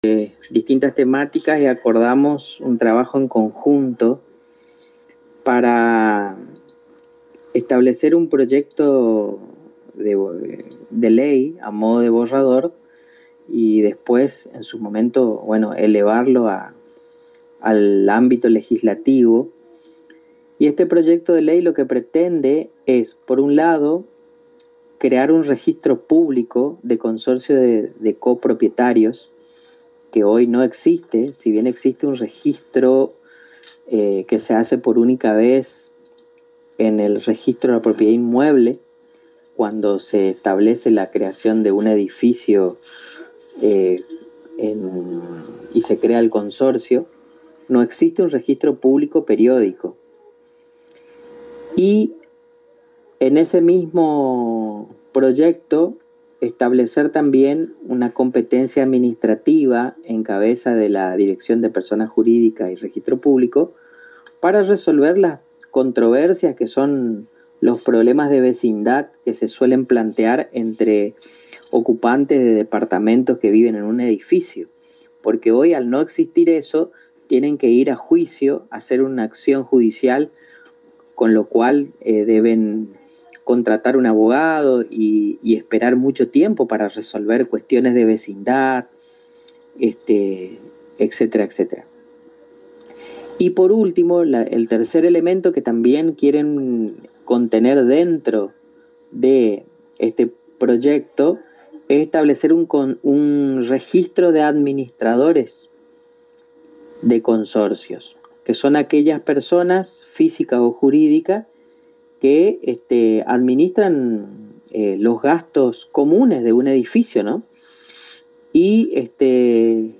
Audio: Dr. Héctor Julio Franco Dir. Gral. Pers. Jur. y Reg. Púb.
El Dr. Franco en diálogo exclusivo con la ANG explicó sobre lo tratado en la mencionada reunión.